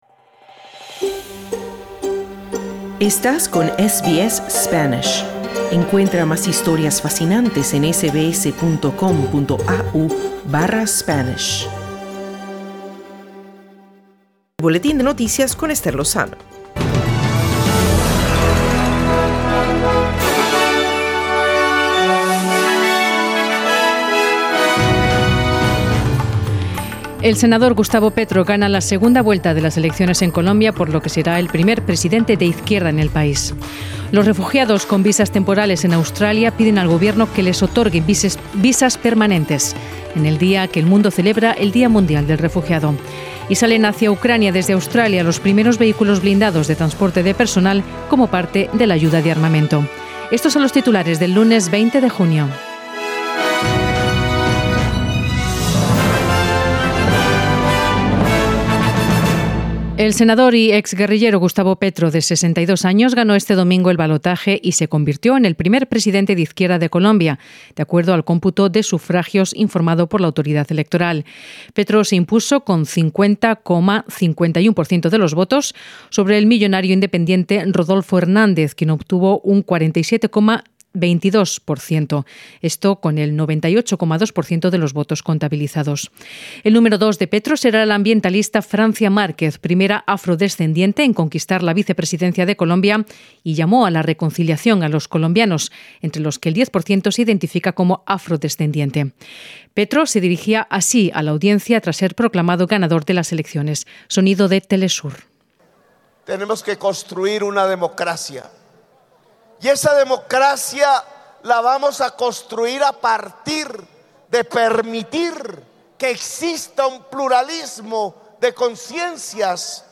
Noticias SBS Spanish | 20 junio 2022